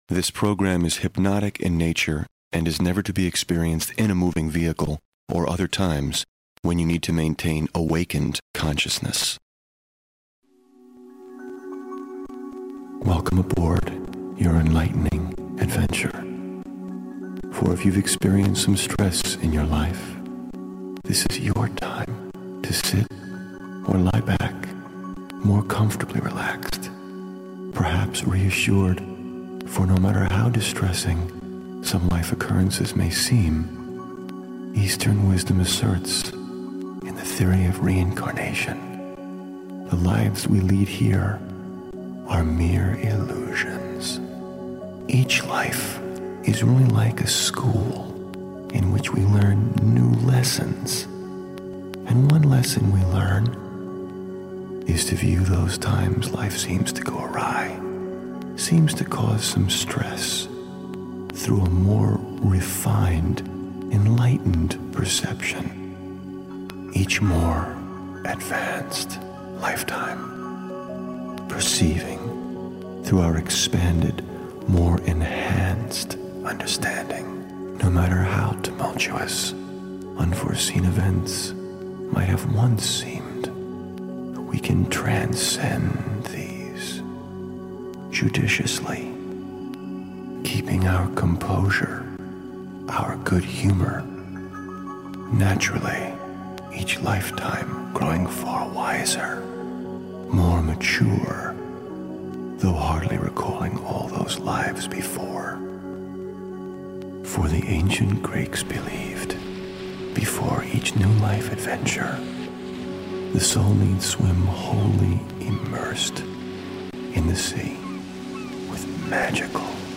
In this audio, we will guide you through the process of achieving deep relaxation through hypnosis.